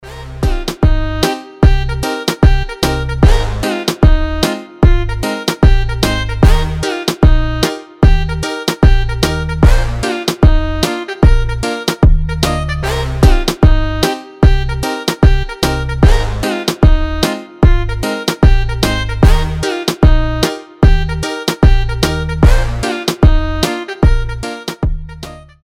• Качество: 320, Stereo
ритмичные
без слов